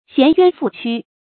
銜冤負屈 注音： ㄒㄧㄢˊ ㄧㄨㄢ ㄈㄨˋ ㄑㄩ 讀音讀法： 意思解釋： 心懷冤枉，身受委屈。